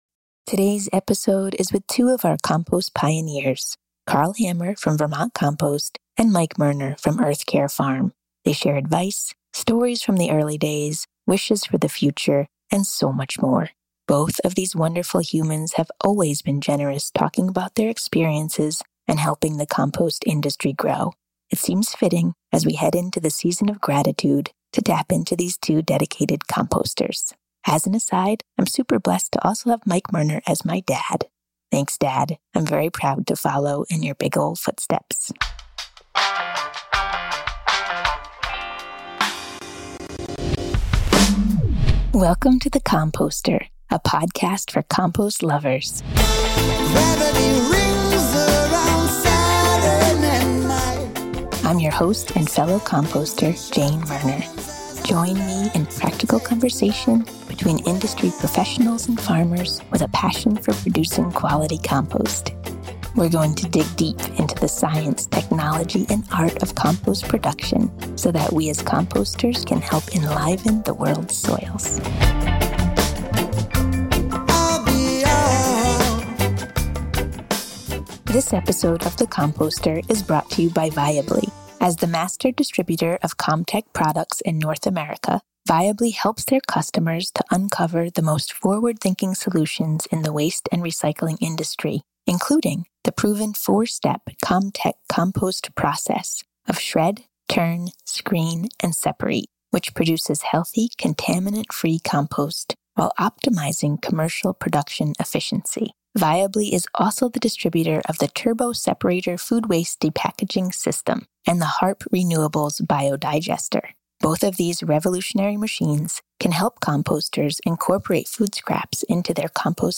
Today's episode is with two of our compost pioneers